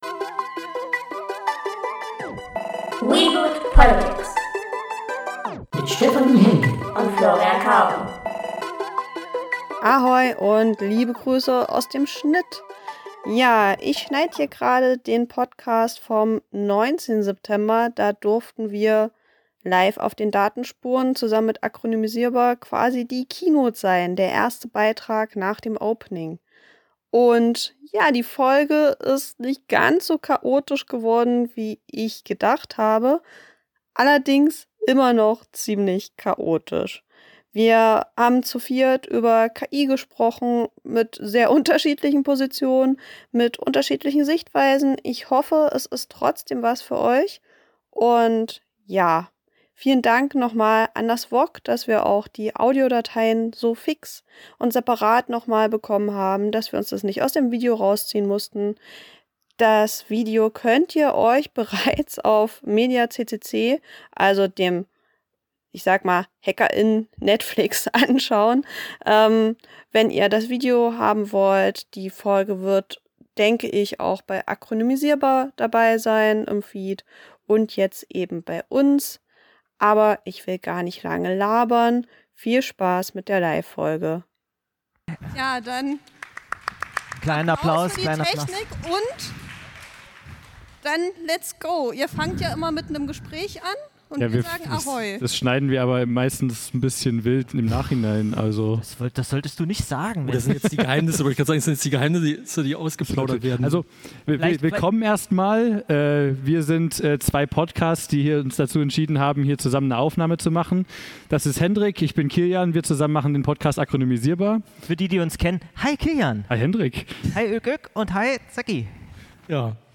Am Freitag den 19. September haben wir zusammen mit Akronymisierbar eine Live-Podcastfolge aufgenommen. Es ging um KI, politische Fails zum Thema und den AI-Act.